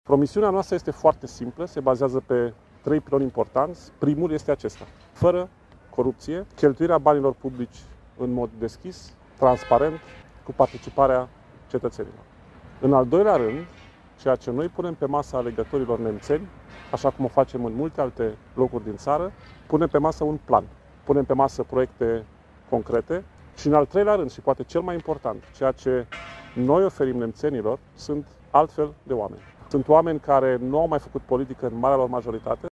Președintele executiv al PLUS, europarlamentarul Dragoș Tudorache, a fost prezent astazi la Piatra Neamț, unde a susținut si o conferință de presă în faţa sediului Primariei din municipiu.